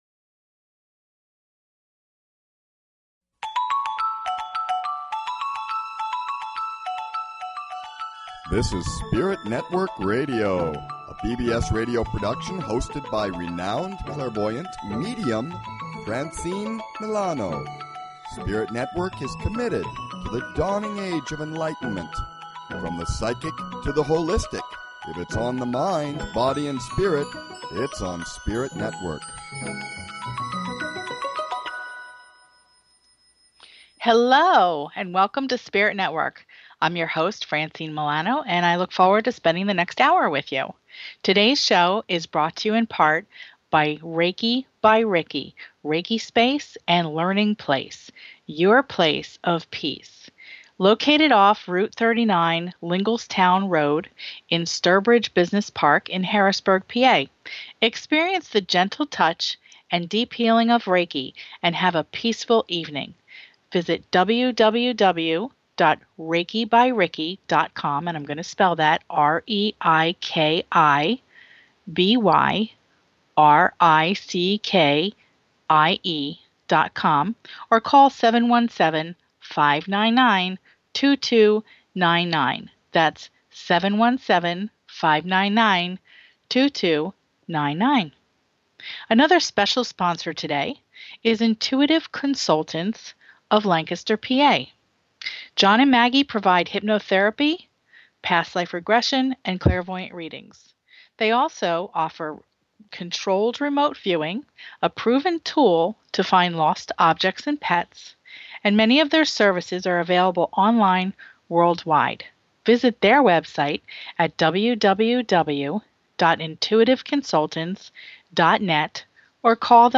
SpiritNetwork is a straight-forward spiritual variety show with something new to look forward to in every show.